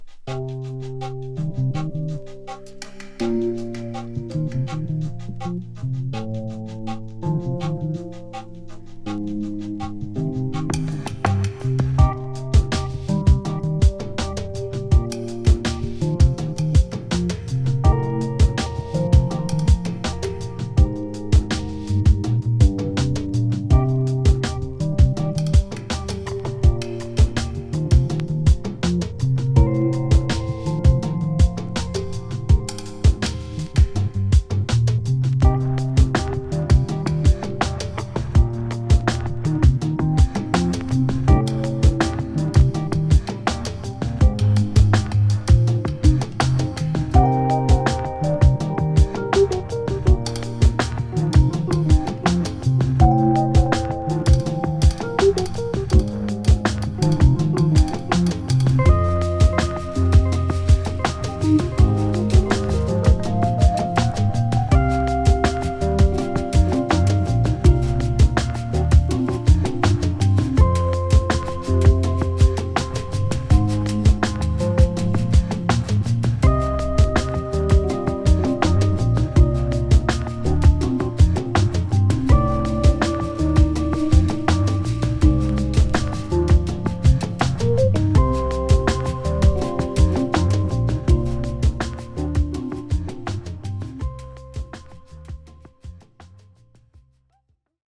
ファンク・ソウルからブラジリアン、そしてハウスとサントラとは思えない驚きの完成度!!!